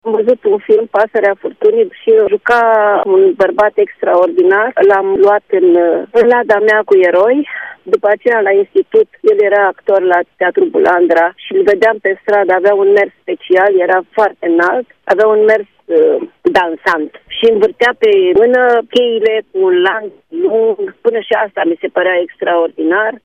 Actrița Rodica Mandache povestește că era o copilă atunci când l-a văzut prima dată pe scenă pe Mircea Albulescu.